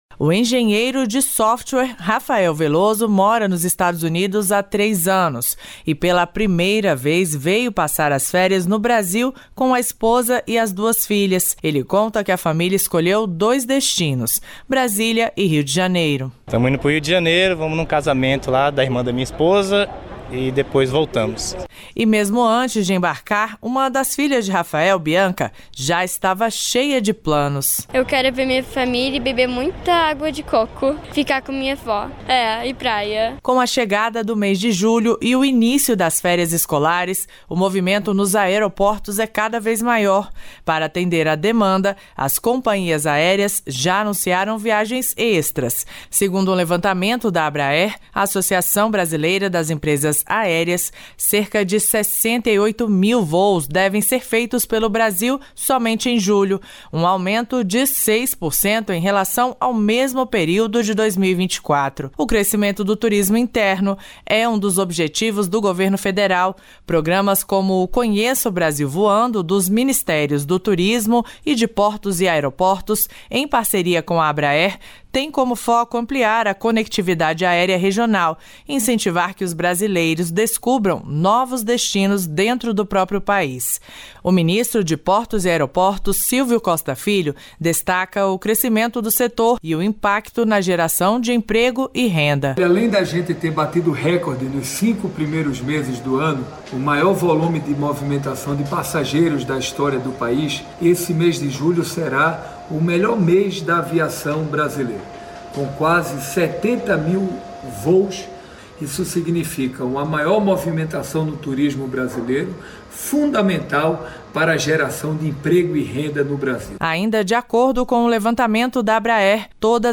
Quem consumir até 80 quilowatts/hora terá a conta zerada, ou seja, não vai pagar pela luz. Caso o usuário ultrapasse este consumo, só será cobrado o valor excedente. Entenda na reportagem.